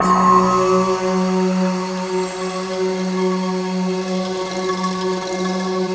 SI1 BAMBO04L.wav